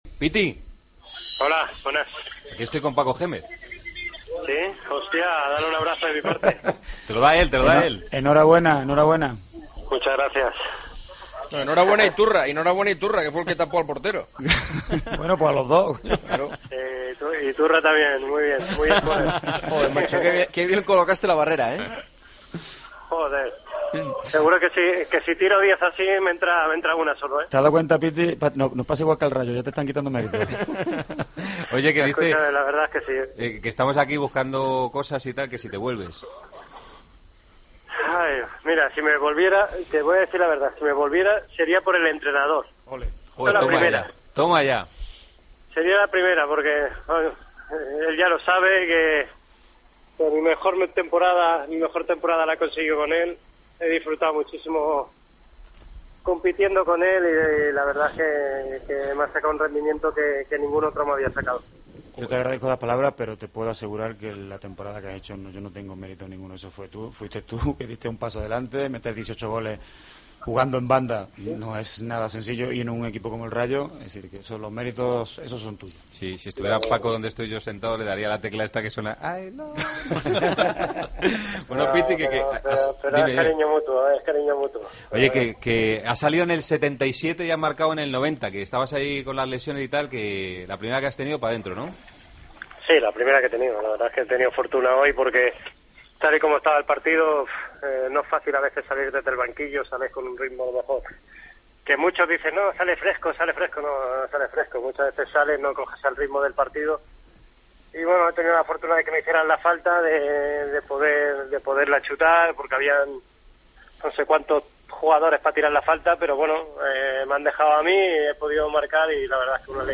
Entrevista a Piti, en Tiempo de Juego: "Si me volviera al Rayo sería por su entrenador"